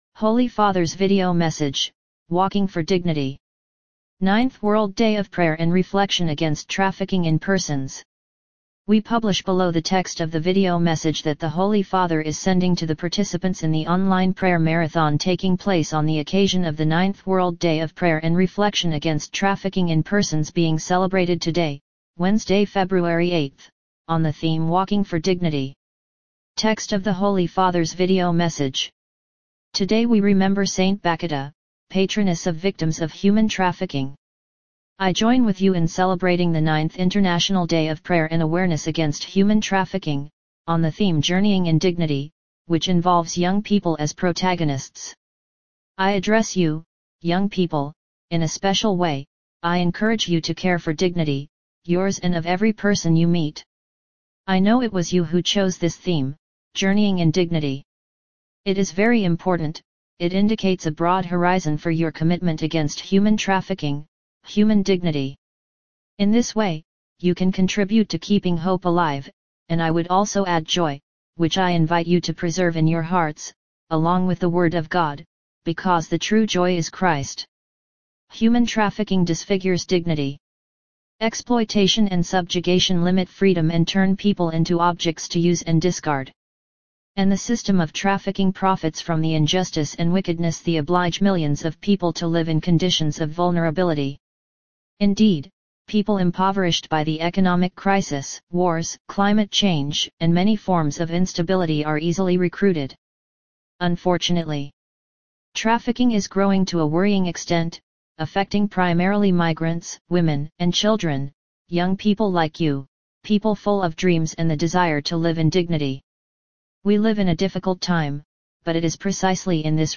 We publish below the text of the Video Message that the Holy Father is sending to the participants in the online Prayer Marathon taking place on the occasion of the 9th World Day of Prayer and Reflection against Trafficking in Persons being celebrated today, Wednesday 8 February, on the theme “Walking for Dignity”: